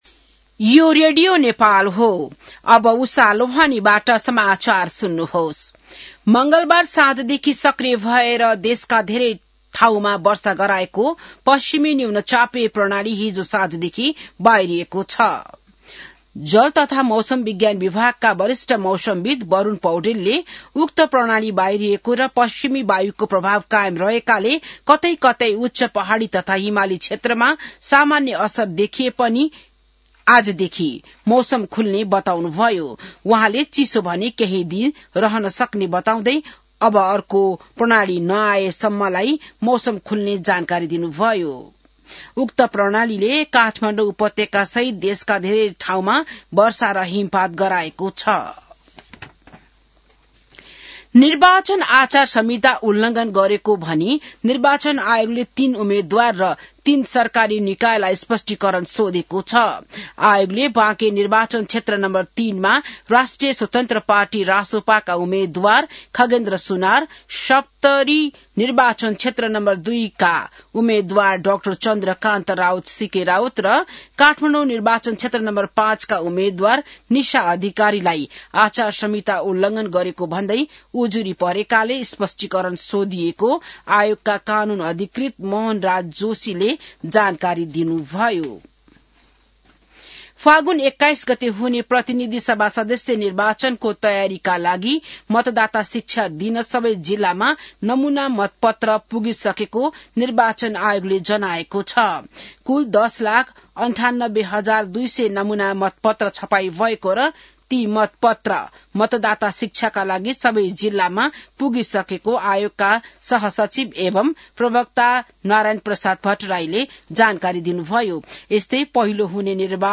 An online outlet of Nepal's national radio broadcaster
बिहान १० बजेको नेपाली समाचार : १५ माघ , २०८२